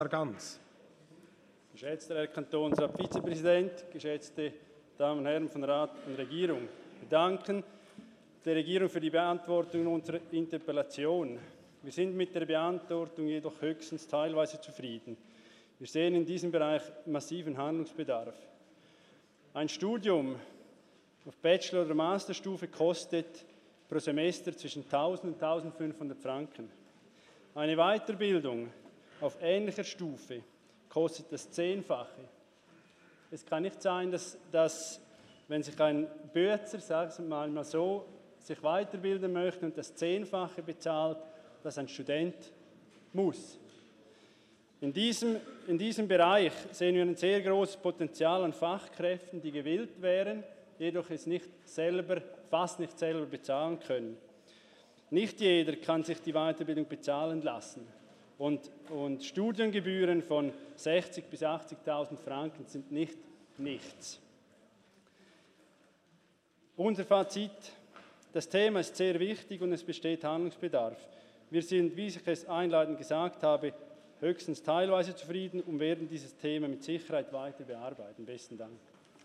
23.4.2019Wortmeldung
Session des Kantonsrates vom 23. und 24. April 2019